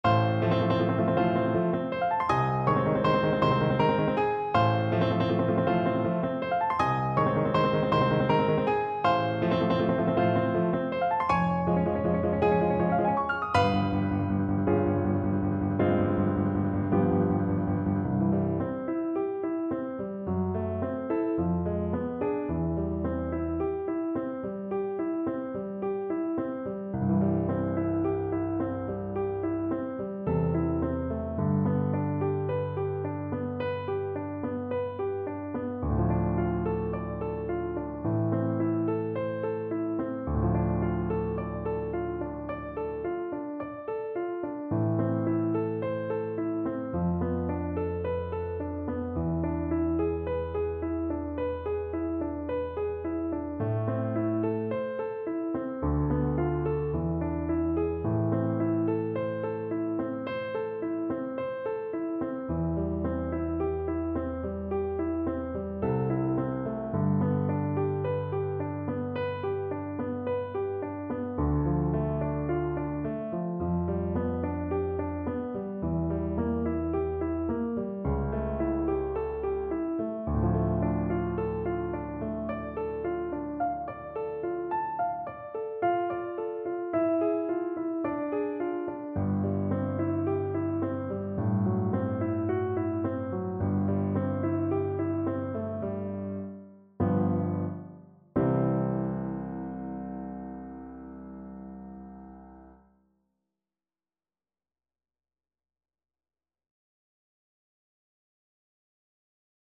6/8 (View more 6/8 Music)
Sostenuto =160 Sostenuto
Classical (View more Classical Flute Music)